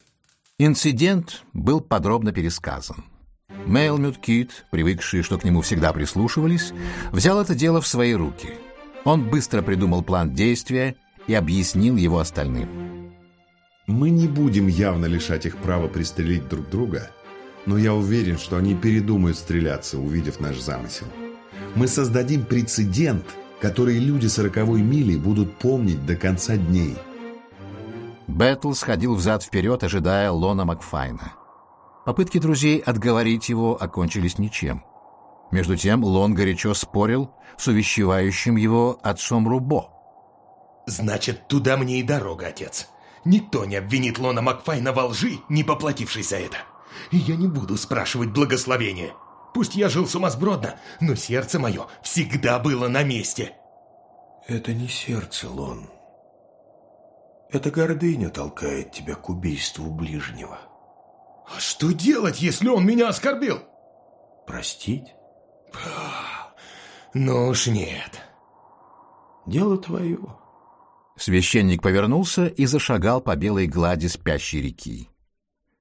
Аудиокнига Люди сороковой мили (спектакль) | Библиотека аудиокниг
Aудиокнига Люди сороковой мили (спектакль) Автор Джек Лондон Читает аудиокнигу Виктор Раков.